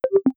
notification-sound.wav